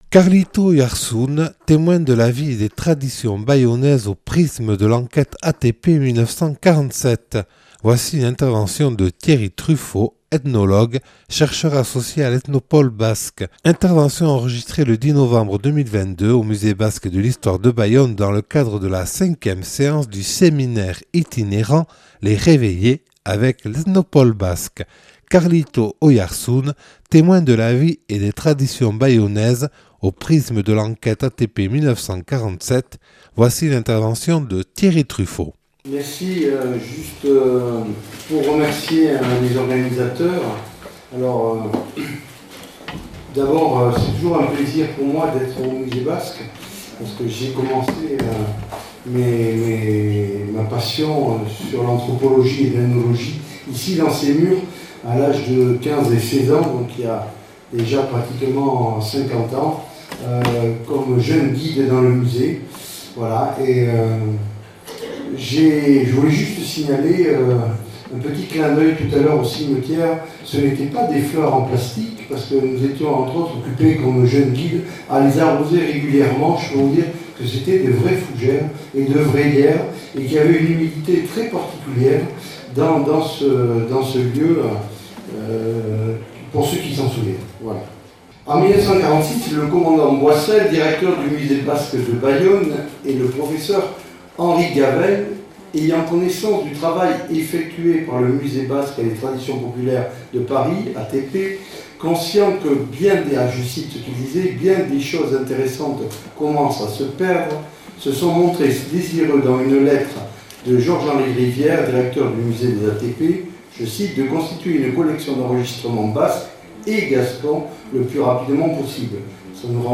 (Enregistré le 10/11/2022 au Musée Basque et de l’histoire de Bayonne dans le cadre de la cinquième séance du séminaire itinérant « Les Réveillées » avec l’Ethnopôle Basque.